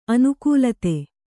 ♪ anukūlate